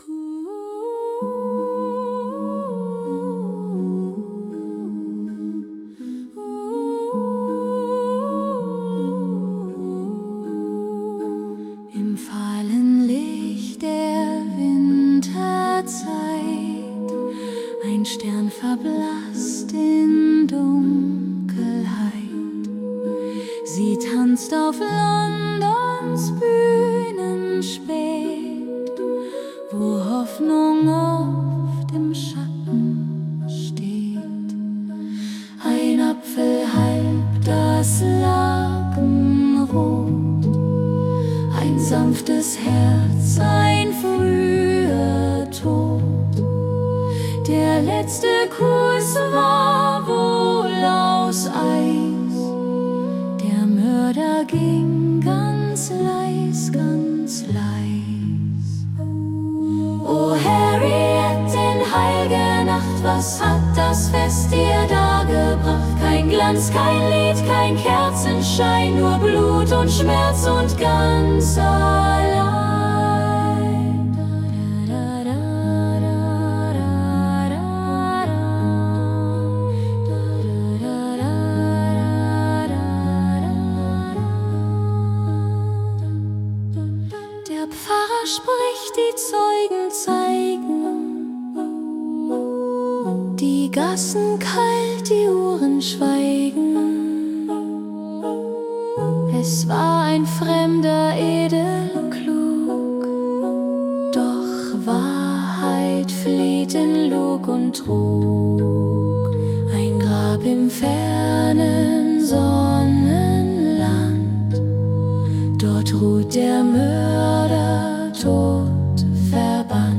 Begeben Sie sich auf eine kurze und atmosphärisch-musikalische Spurensuche nach einem Mörder, der im Schutz des Weihnachtsfriedens zuschlug und im berüchtigten Londoner Nebel verschwand, ohne jemals gefasst zu werden.